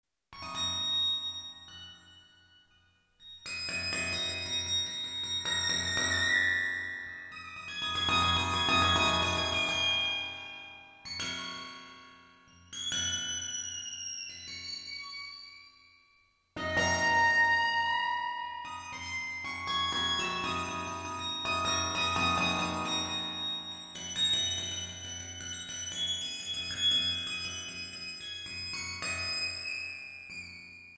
Инструмент — Waterphn (синтезированный).